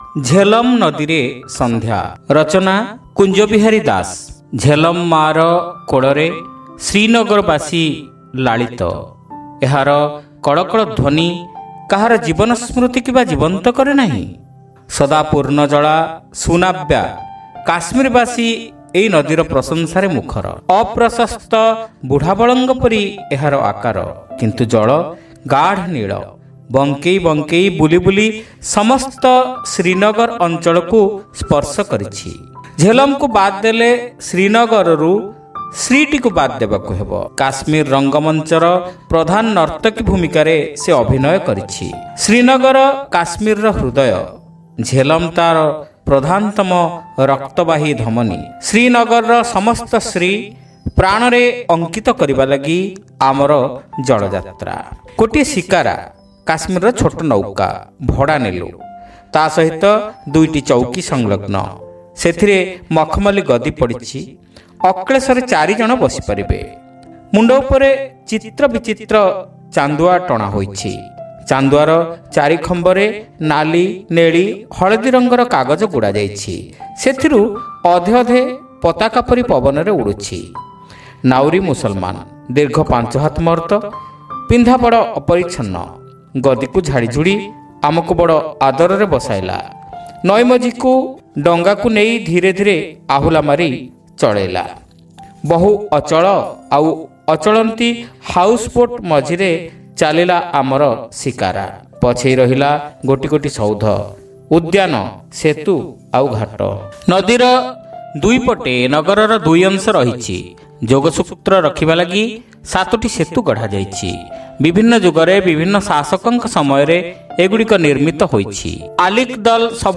Audio Story : Jhelum Nadire Sandhya